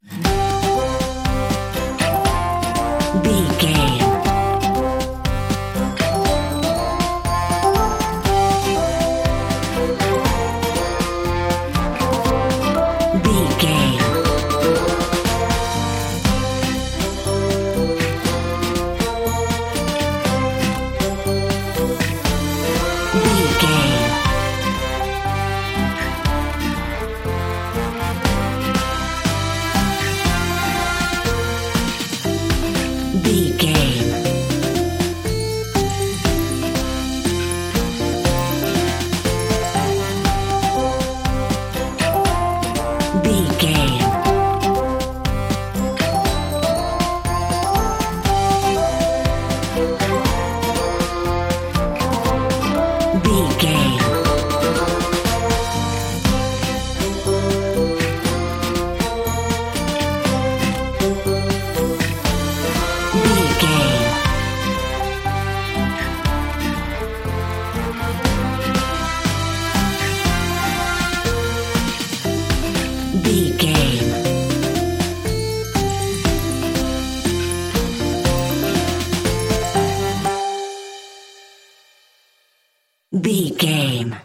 Mixolydian
Fast
bouncy
cheerful/happy
uplifting
acoustic guitar
bassoon
brass
drums
orchestra
sleigh bells
violin
trumpet
synthesiser
strings